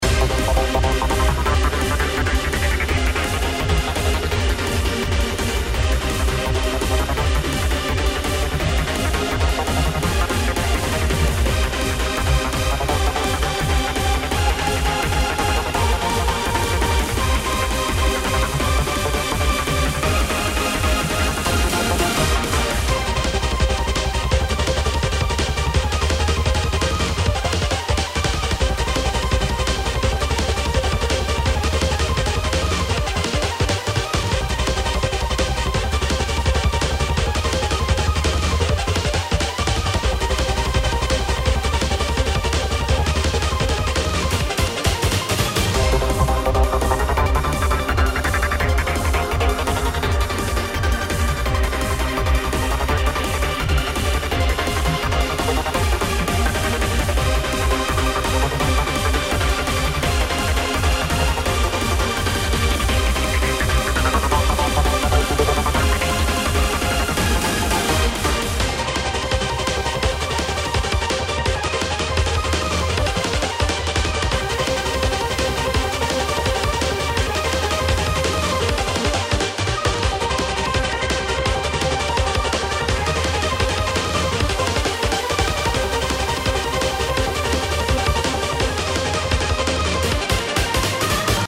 💬宇宙のように壮大で底知れない謎を秘めたキャラあるいは概念をイメージした戦闘BGMです。
曲の雰囲気的に弾幕STGやSF系の世界観に合いそうな気がしています。